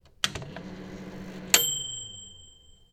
MicrowaveDing
ding done drone microwave power switch sound effect free sound royalty free Nature